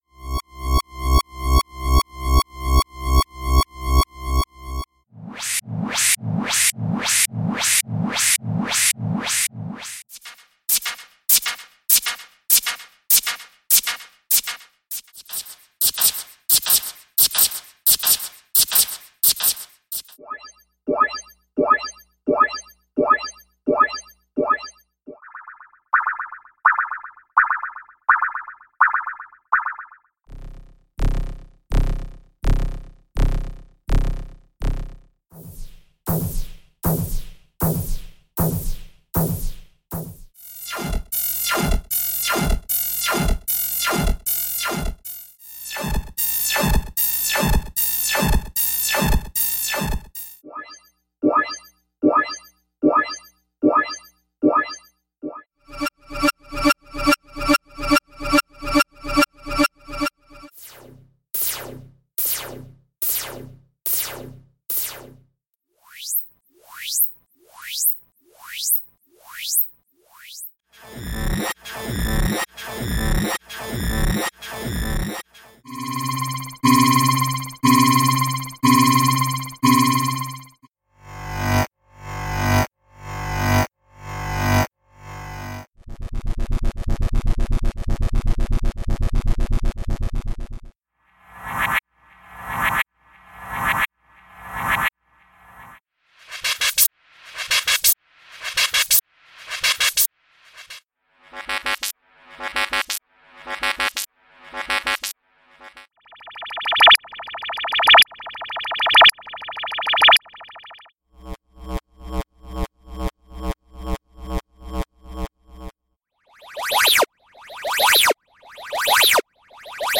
Sound Effects - SIGNALS - V1 - p1
Signals Actual Length: 1 Minute (60 Sec) Each Sound.